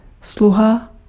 Ääntäminen
Synonyymit middel apparaat gereedschap hulpmiddel bijstand helper assistent toeverlaat assistentie adjunct ondersteuning steun toedoen medewerking behulp heul famulus Ääntäminen Tuntematon aksentti: IPA: /ɦʏlp/ IPA: [ɦʏɫp]